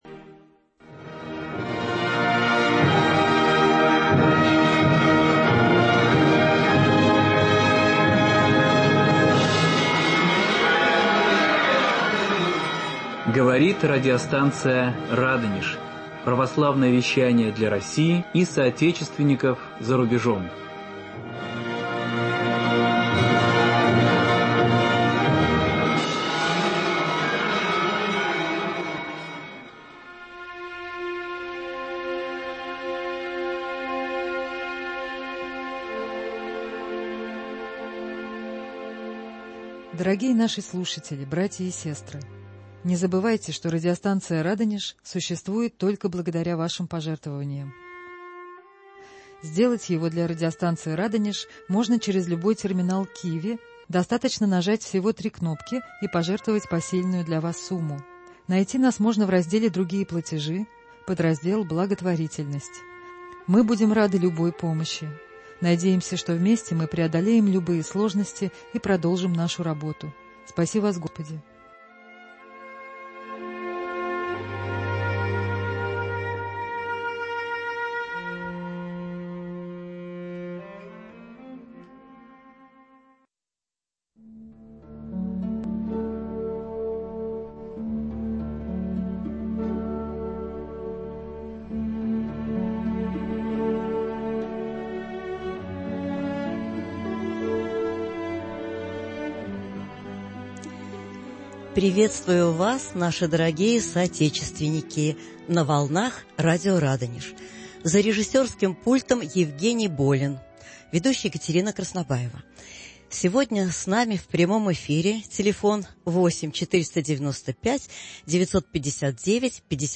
В студии радио